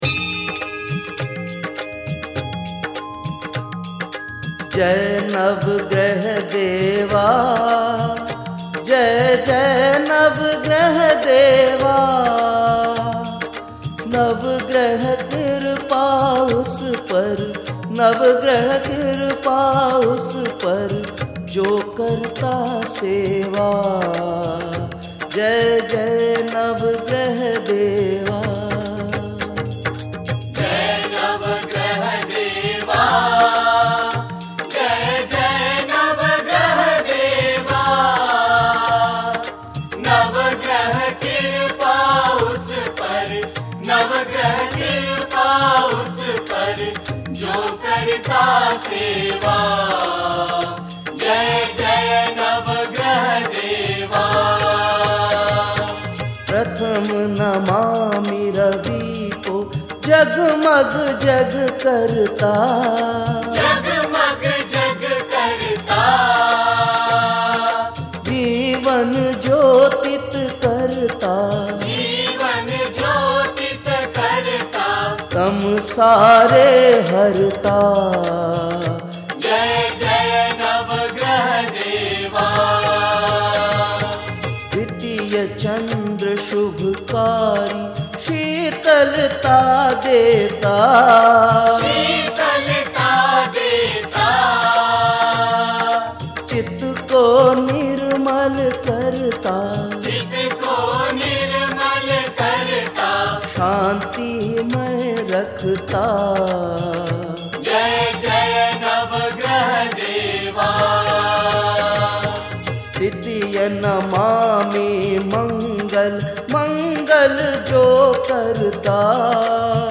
Hindi Devotional Songs
Aartiyan